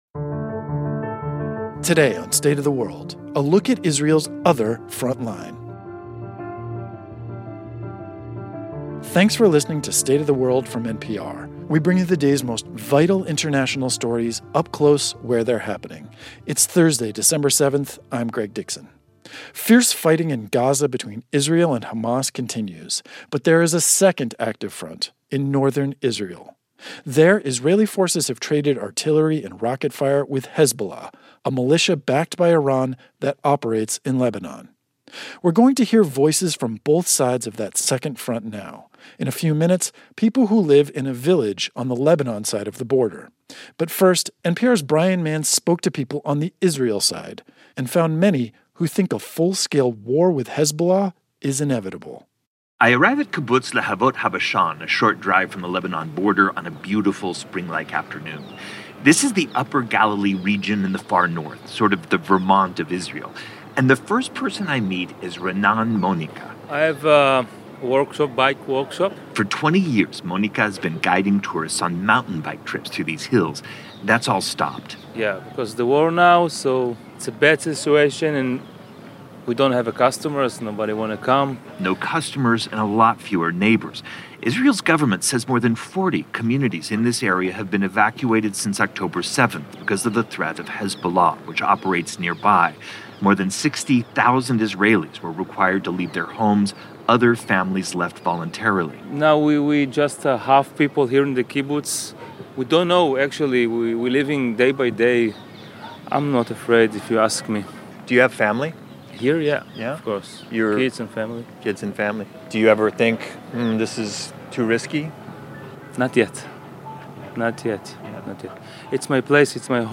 Israeli forces have traded artillery and rocket fire with Hezbollah, a militia backed by Iran that operates in Lebanon. We hear the voices of people living on both sides of the Israel-Lebanon border.